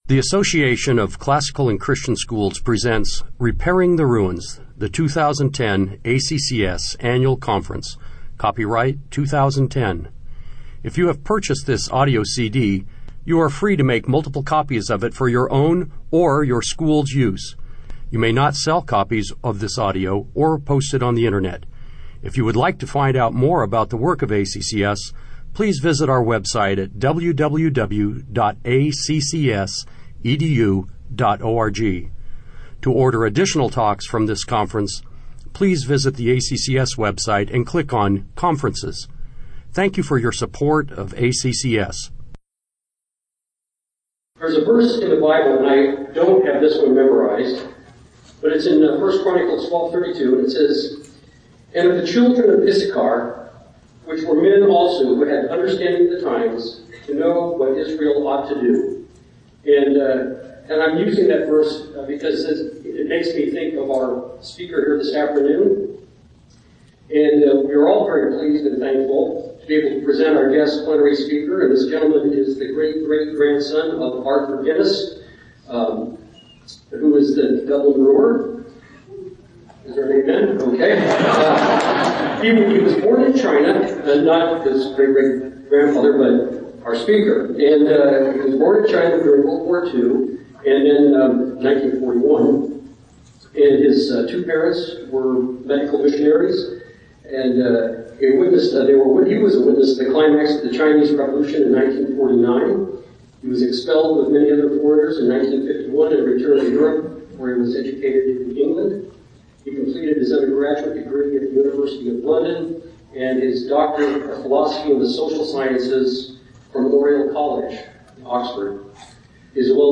2010 Plenary Talk | 0:47:08 | All Grade Levels, Culture & Faith